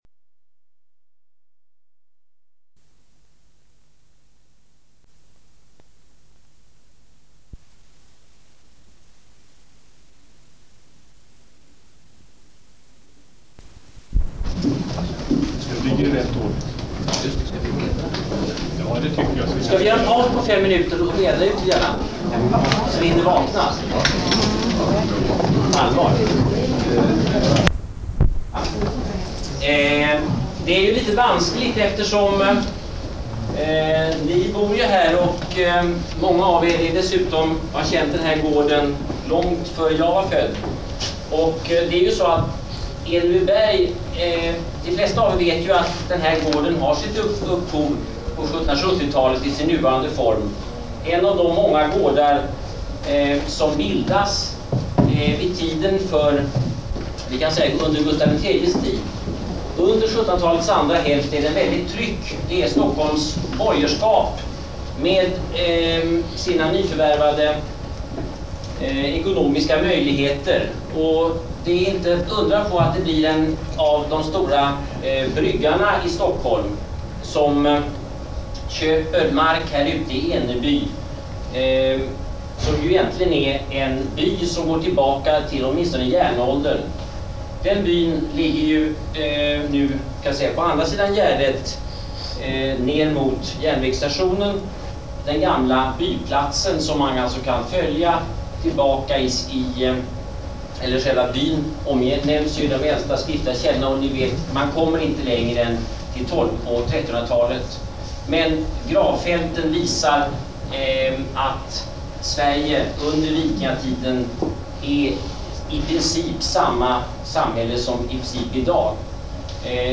Föredrag på gården.